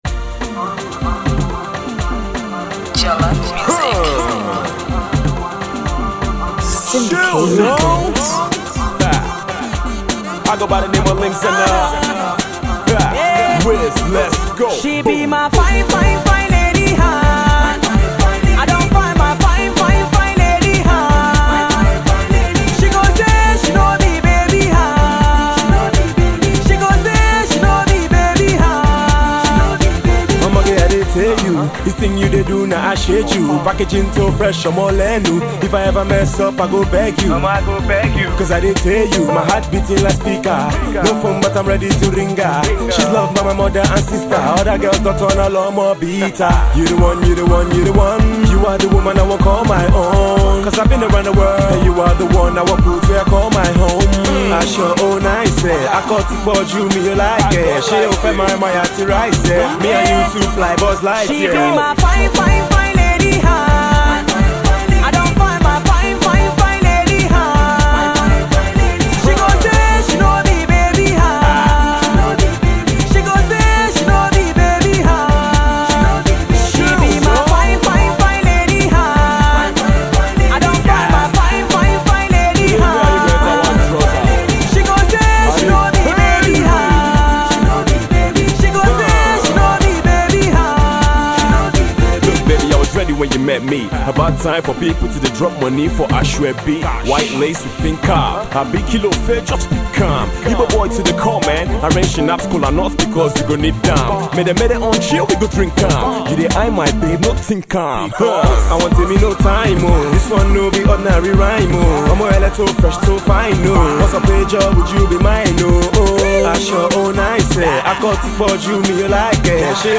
soukous-inspired guitars